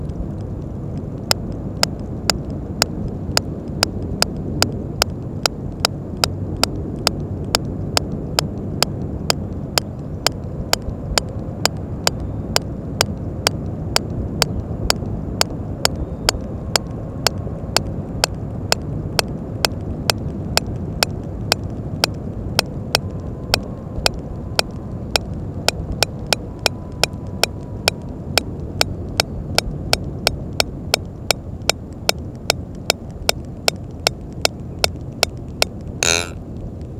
Deep dive, clicks and buzz during the discent phase.